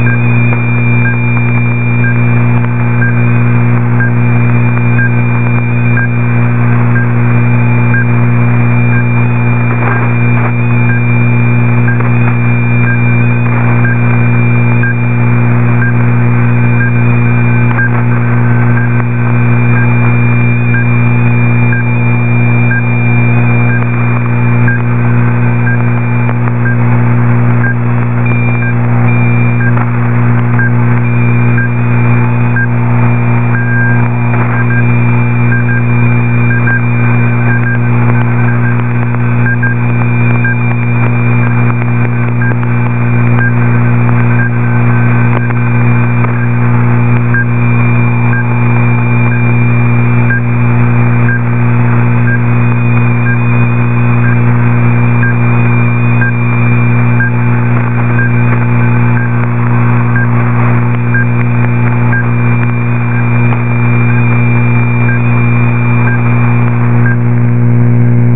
Recordings of Time Signal Stations